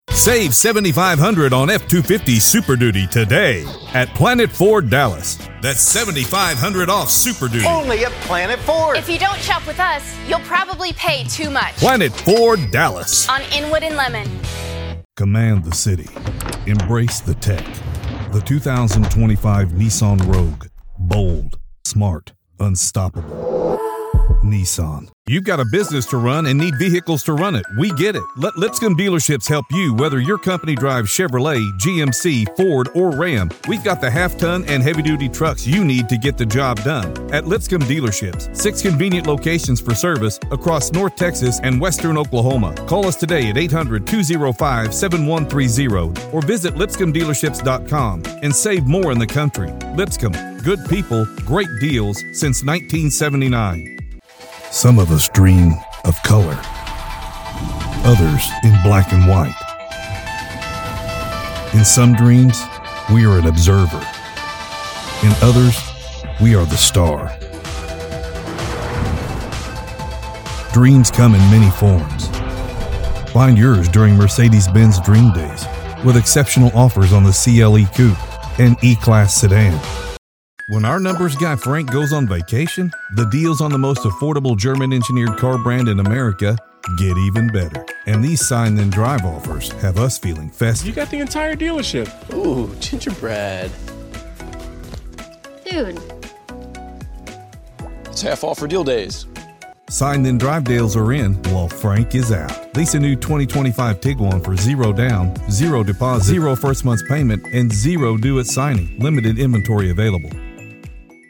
Male
Southern, Anthem, storyteller, conversational, non announcer, movie trailer, Simple, clear, direct, honest, trustworthy, confident, enthusiastic, conversational, Amiable, Announcer, Approachable, Athletic, Attitude, Attractive, Audiobook, Authoritative, Bank, Baritone, Believable, Bright, Broadcast, Business, Call to Action, Calming, Caring, cool
Radio Commercials
Automotive Spots For Radio